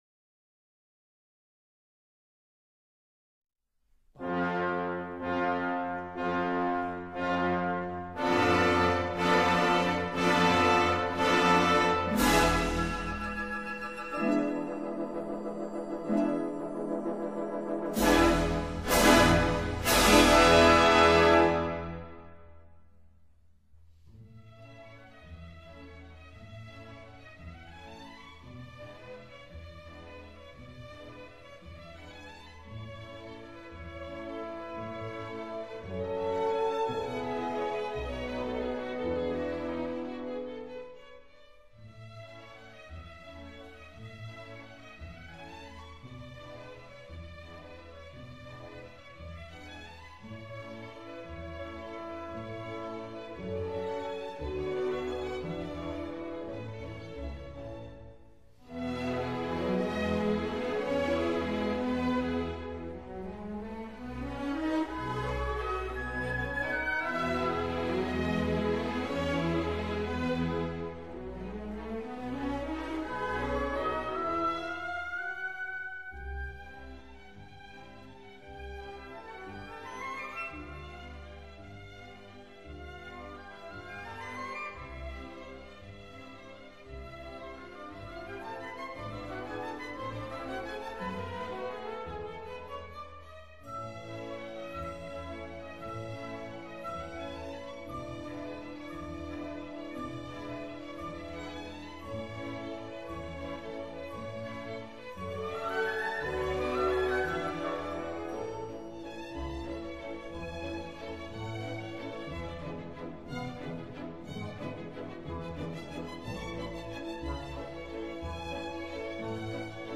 faust-Charles-Gounod-Faust-Musique-de-ballet.mp3